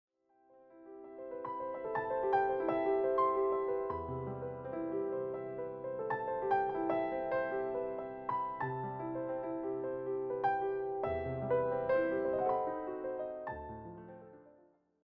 all rendered as solo piano pieces.
intimate, late-night atmosphere